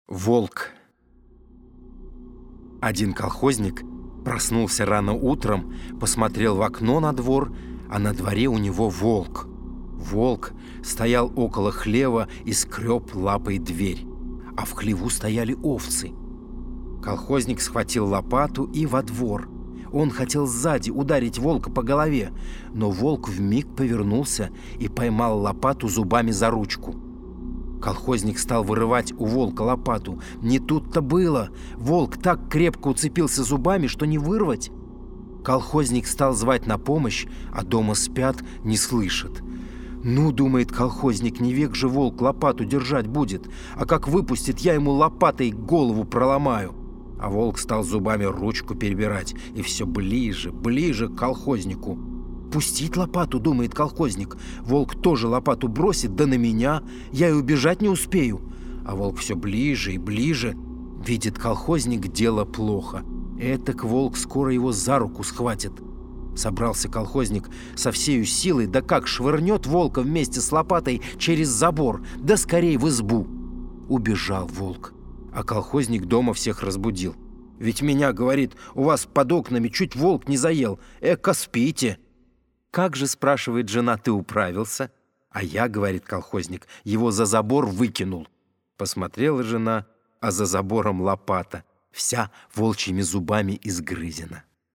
Аудиорассказ «Волк»